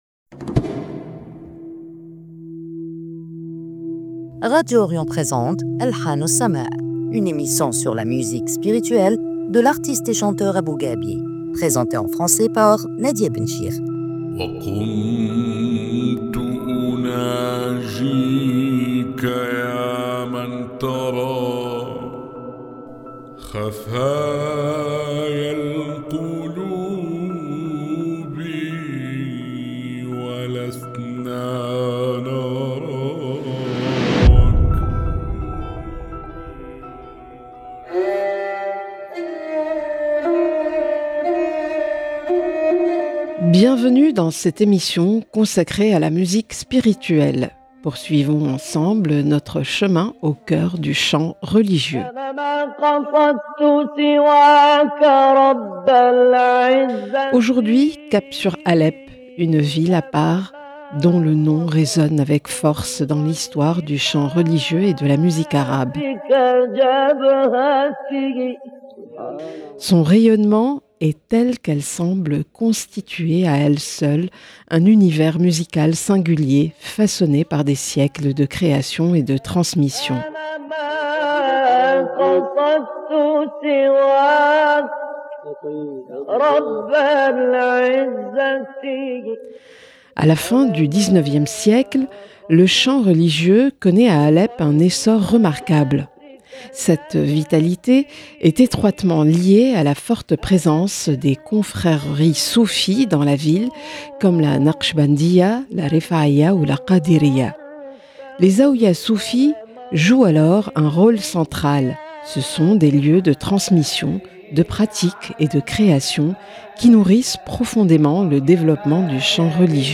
émission